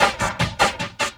45LOOP SD2-L.wav